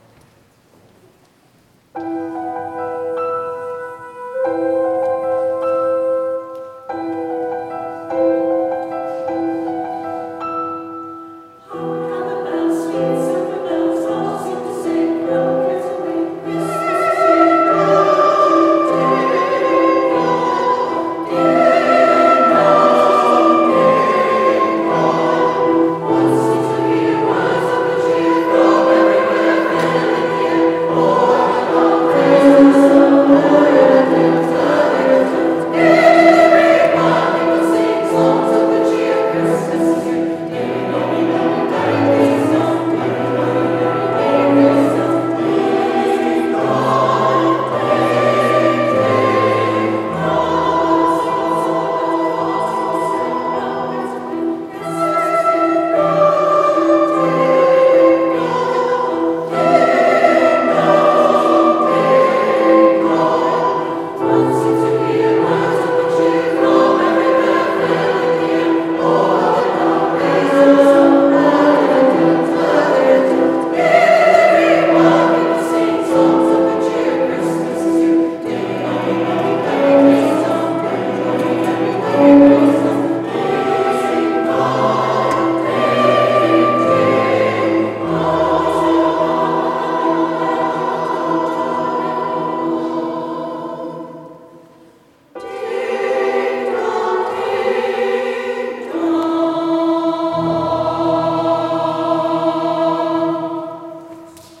December 7, 2025 - St. Philomena Christmas Concert
2025 St. Philomena Chorus